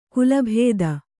♪ kulabhēda